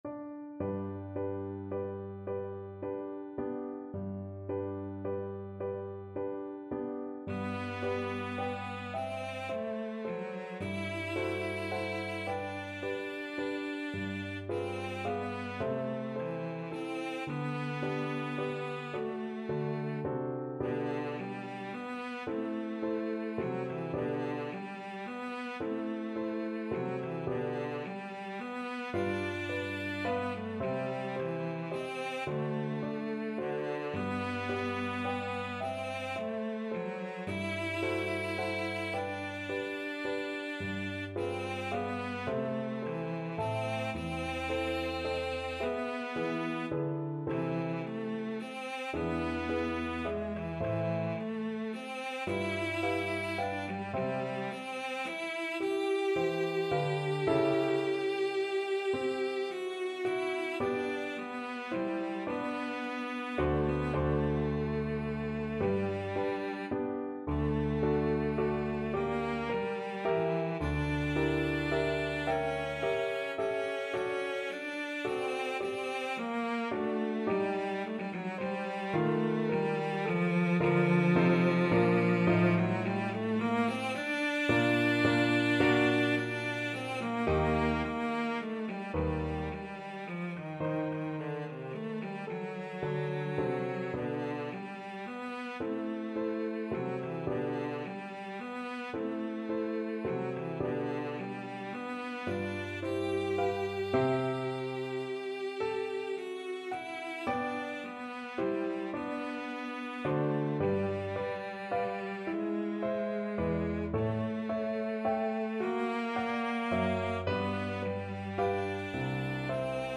Cello
Andante .=36
F#3-G5
G major (Sounding Pitch) (View more G major Music for Cello )
6/8 (View more 6/8 Music)
Classical (View more Classical Cello Music)